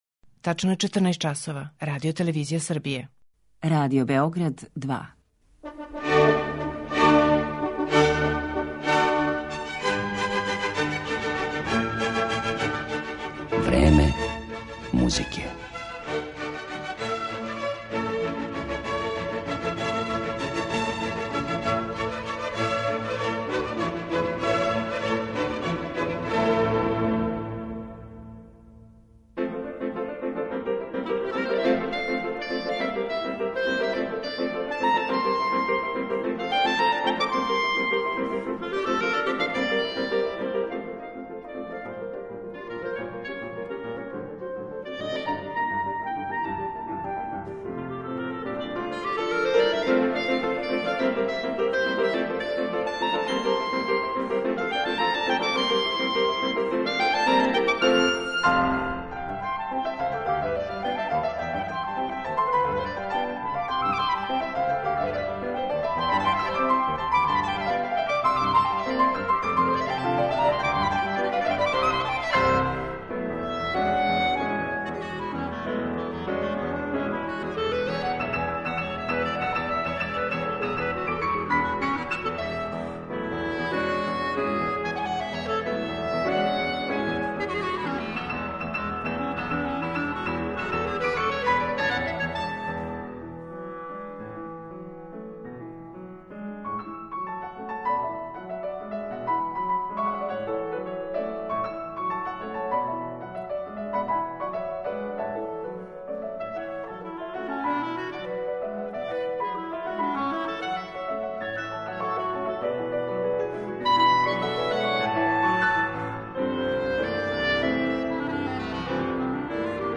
кларинет